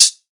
Short Hat Sound C# Key 66.wav
Royality free hat sound sample tuned to the C# note. Loudest frequency: 6155Hz
short-hat-sound-c-sharp-key-66-Cpd.mp3